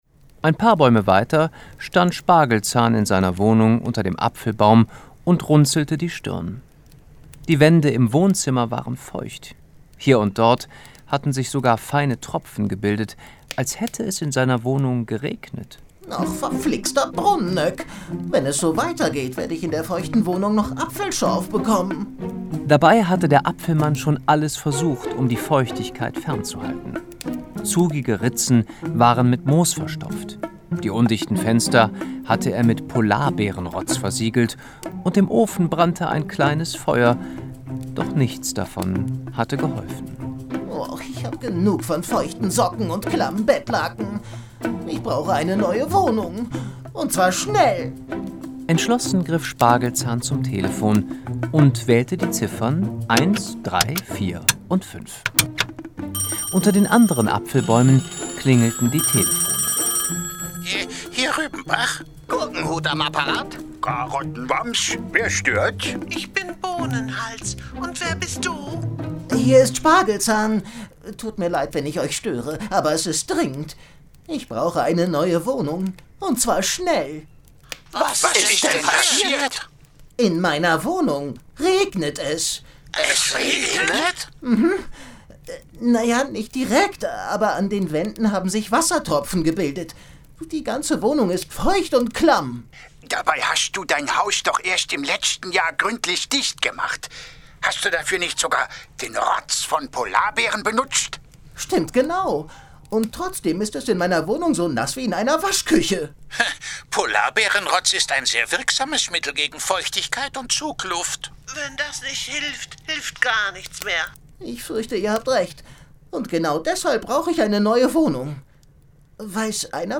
Hörspiel.